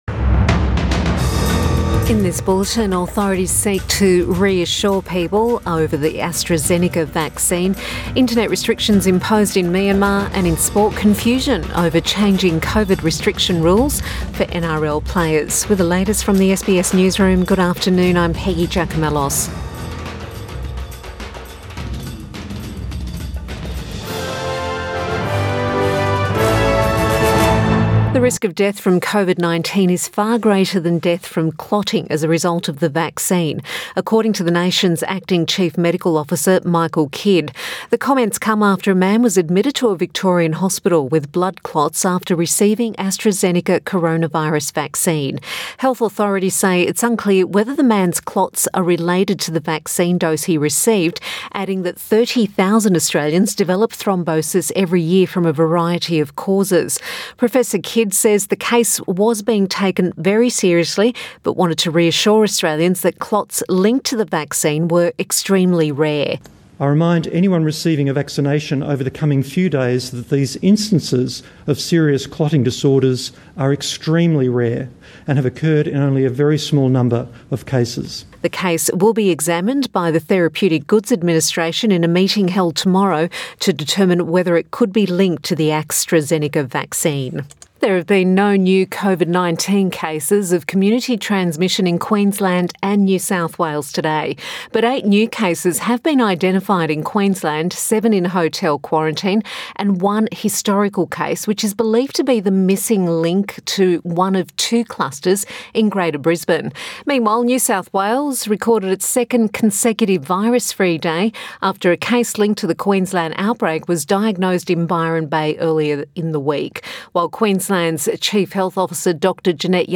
PM bulletin 2 April 2021